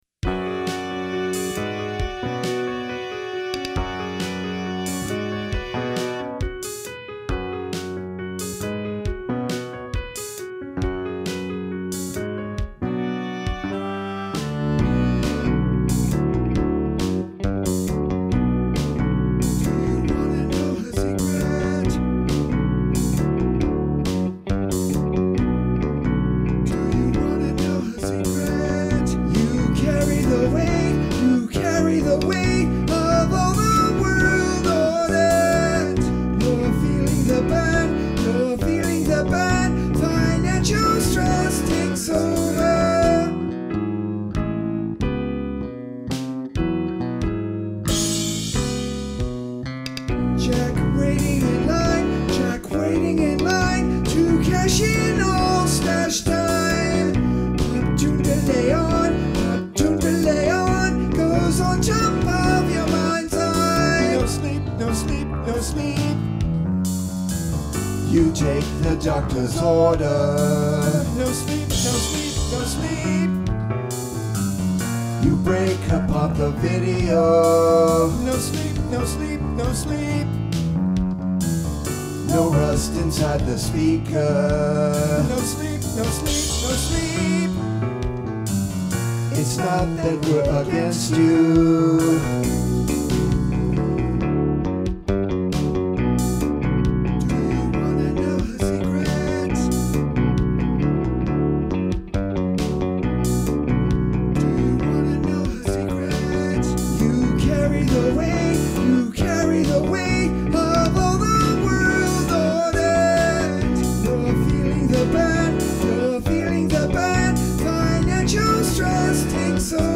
guitar, bass guitar, vocals